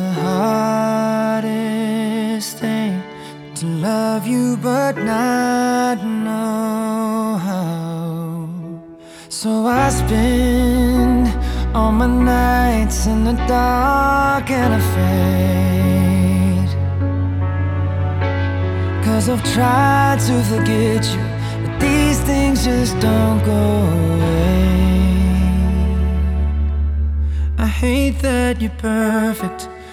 • Soundtrack